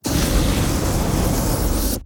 Firespray 2.wav